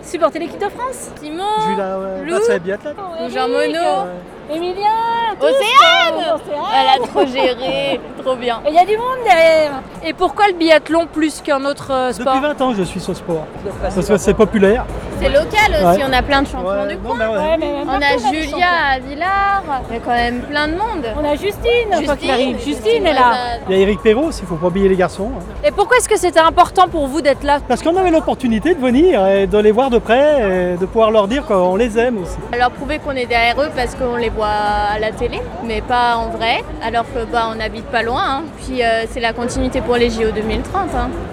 A quelques sièges de là se trouvait toute une famille d’Ugine, fan de biathlon. Ils ont fait le déplacement à 6 pour la cérémonie.